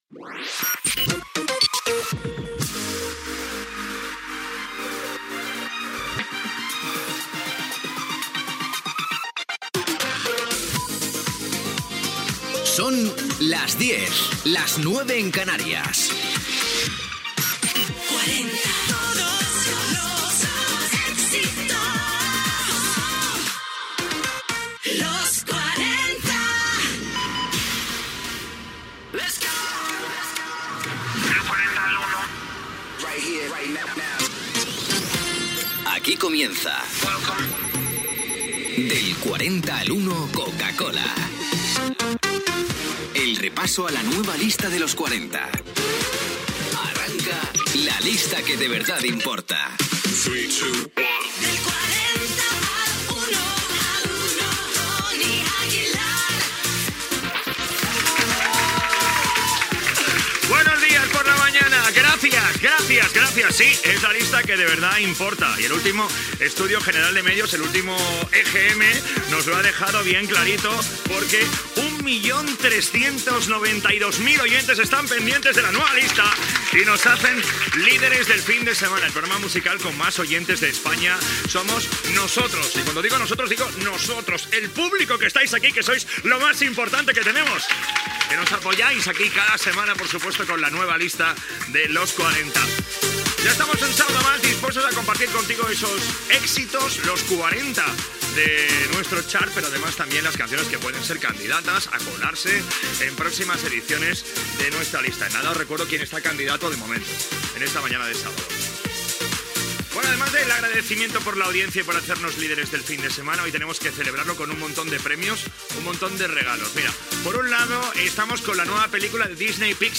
Hora, indicatiu de la ràdio, careta i inici del programa amb dades d'audiència, estrena cinematogràfica, concurs i el repàs a la llista de Los 40
Musical
FM